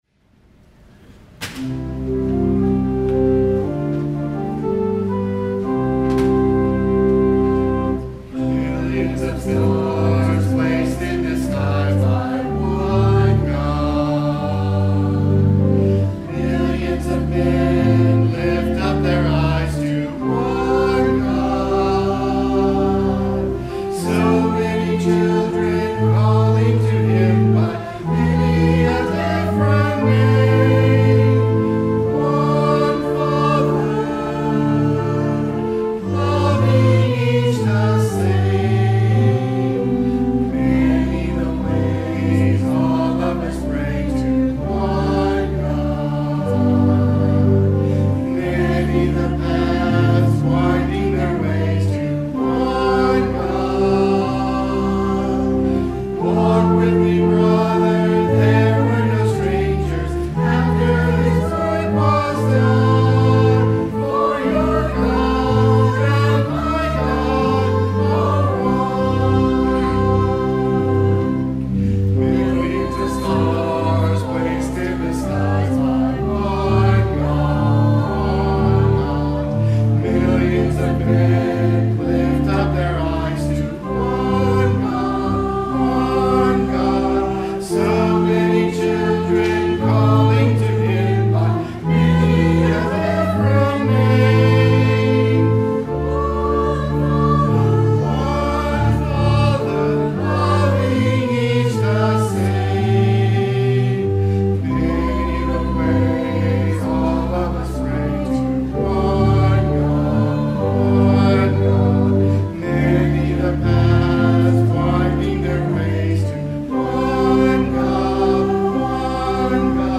Anthem